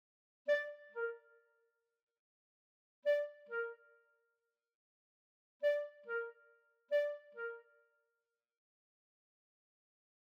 Granted, I’d already spotted that the two clarinets, playing in unison, are extremely cuckoo-like.
Here is Beethoven’s clarinet cuckoo, followed by a cuckoo that was recorded in June 2019 that sounds pretty similar. (Yes, I did deliberately choose a recording of the cuckoo at the same pitches).
beethoven-6-cuckoo-doubled.mp3